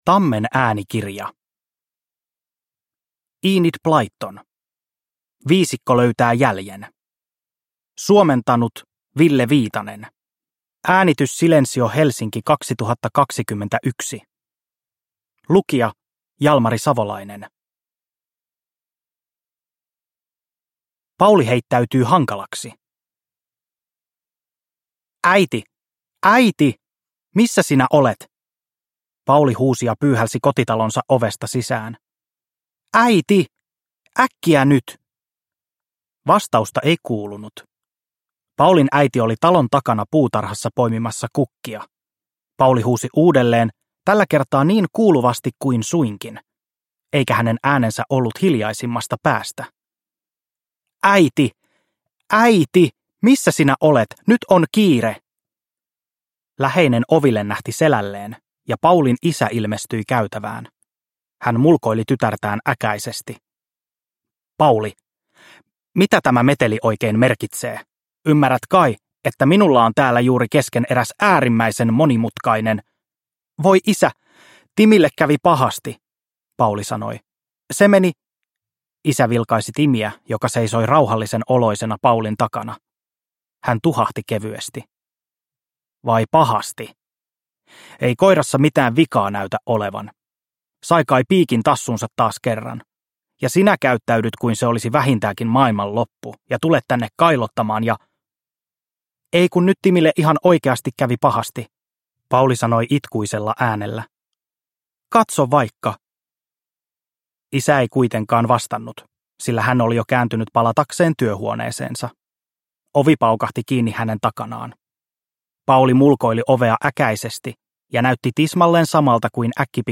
Viisikko löytää jäljen – Ljudbok – Laddas ner